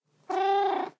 purreow1.ogg